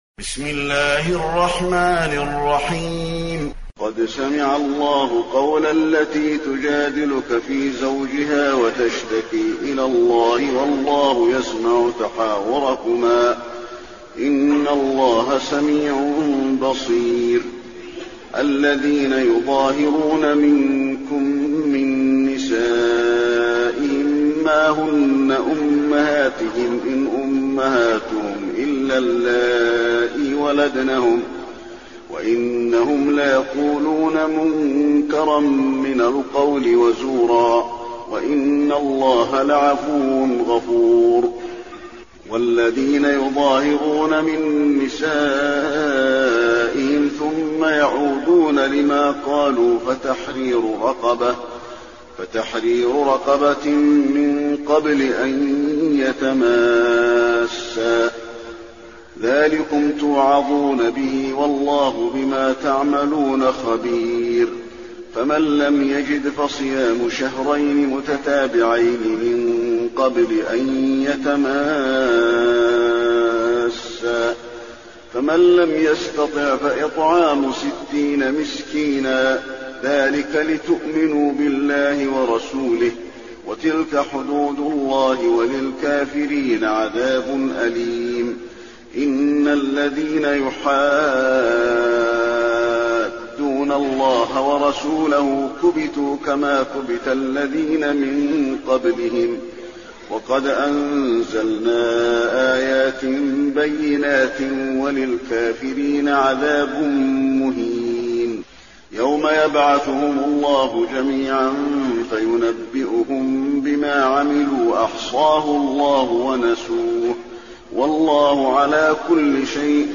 المكان: المسجد النبوي المجادلة